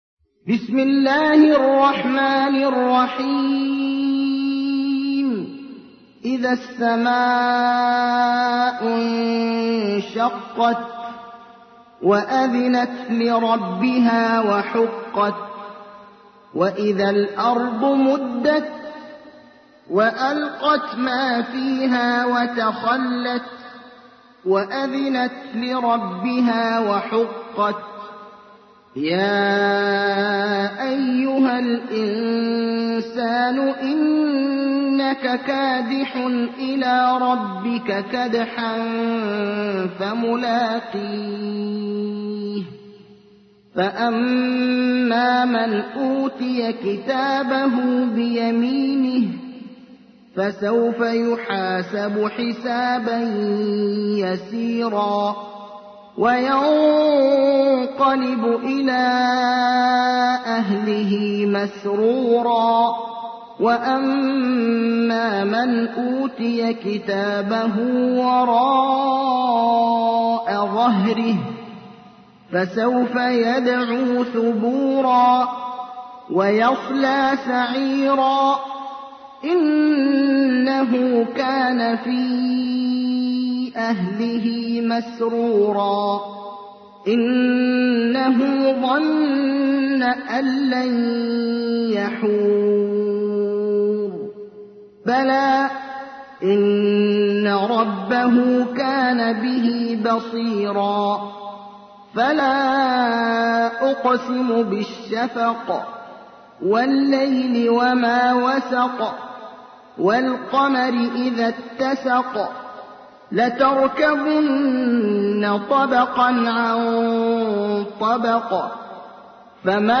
تحميل : 84. سورة الانشقاق / القارئ ابراهيم الأخضر / القرآن الكريم / موقع يا حسين